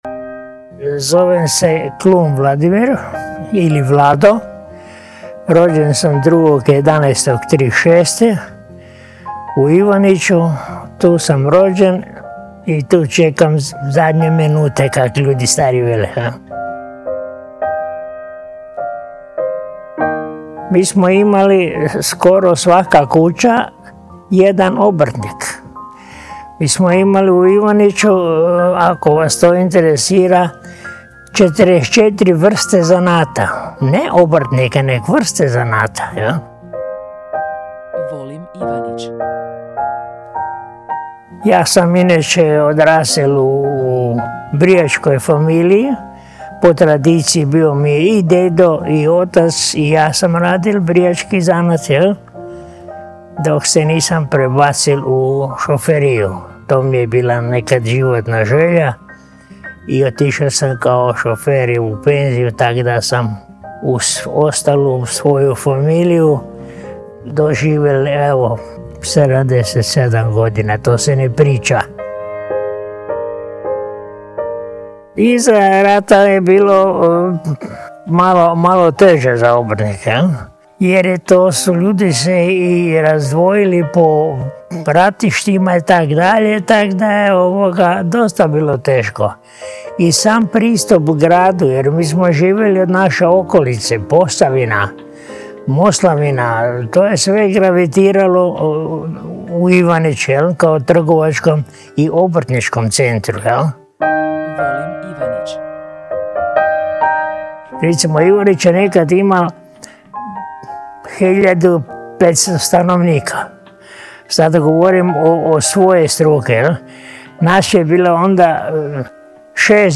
Originalni audio zapis razgovora